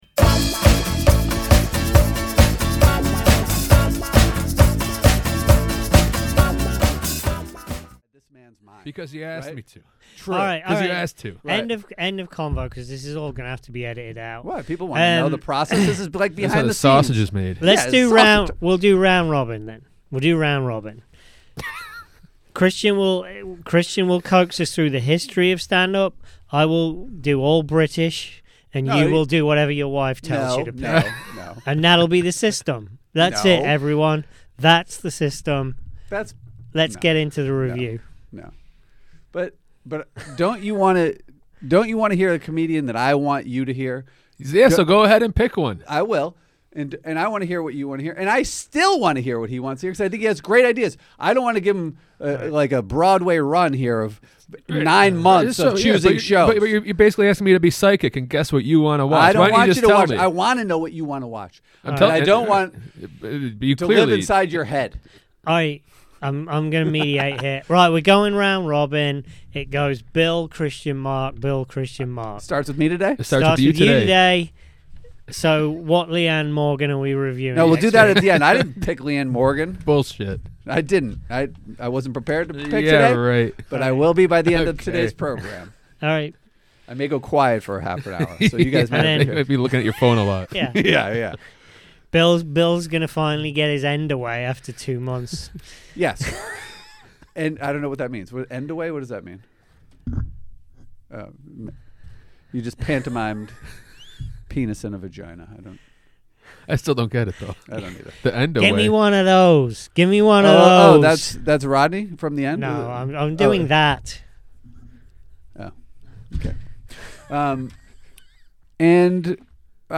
Be thankful that we cut out 10 minutes of arguing over how the specials we review get picked and you only get the last 2 minutes. Then it's a live holiday gift exchange followed by a discussion of holiday show gimmicks and the 'Schnitzelbank' before we finally we get to Rodney Dangerfield's 1983 special It's Not Easy Bein' Me.